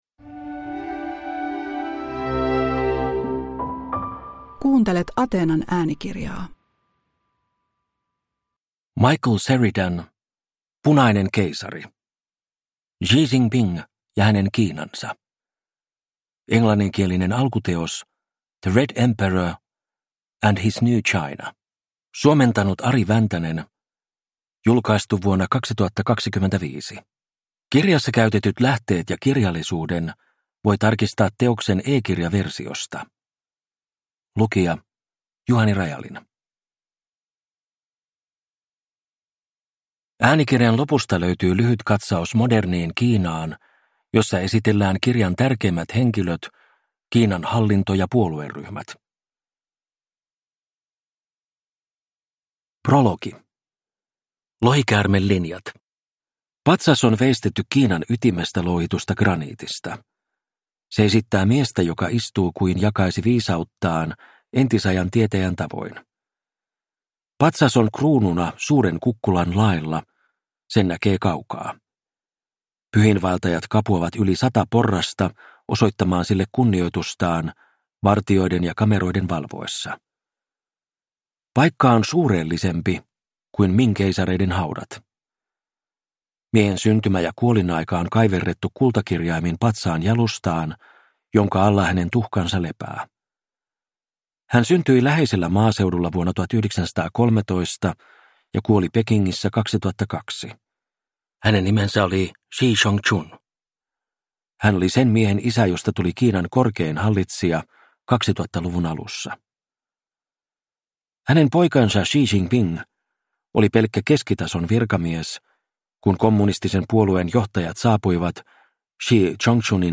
Punainen keisari – Ljudbok